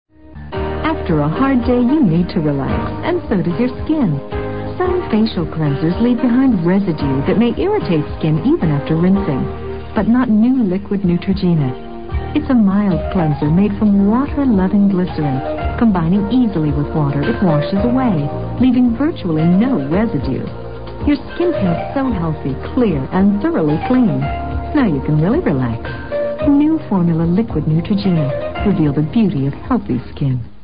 译文：（一男子坐在那时进行自我表白。）